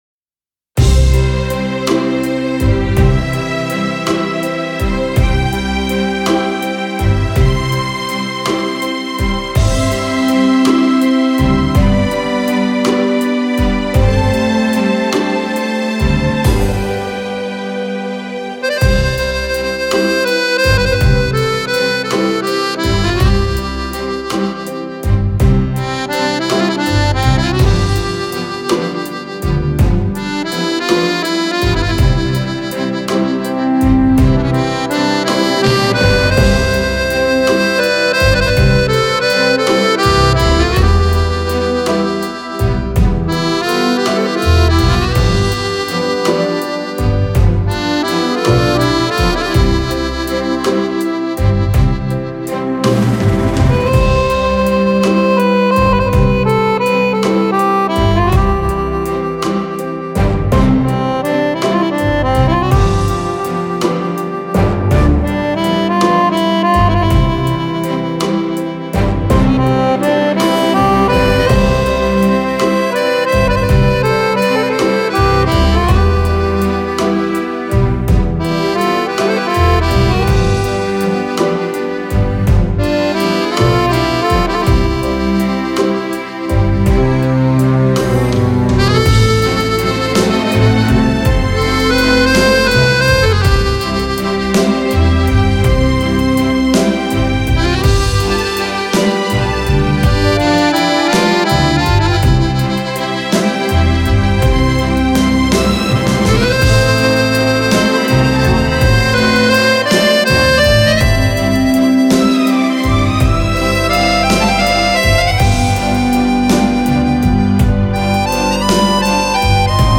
19 ballabili per Fisarmonica